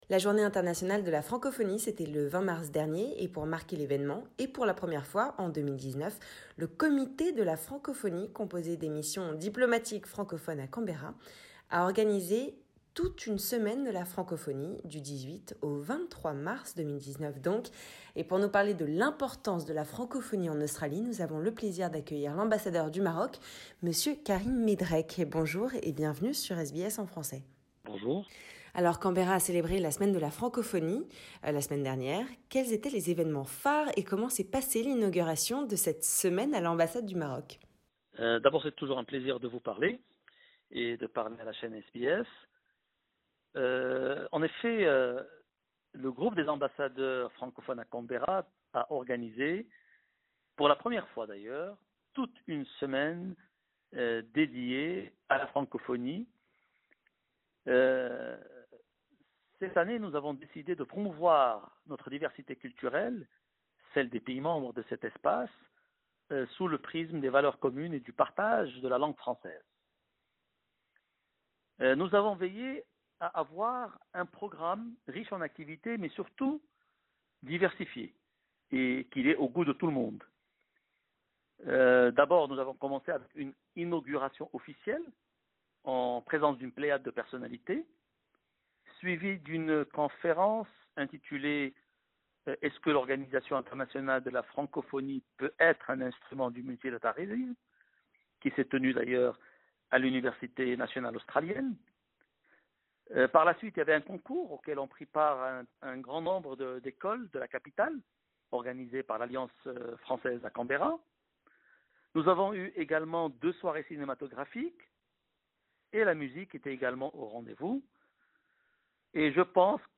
Le 20 mars dernier c'était la Journée de la Francophonie et pour rendre hommage à la langue française, un comité de représentants du corps diplomatique francophone à Canberra a organisé toute une semaine, du 18 au 23 mars 2019 autour de ce thème. Pour nous en parler, sur les ondes de SBS en français, l'Ambassadeur du Maroc en Australie, Monsieur Karim Medrek.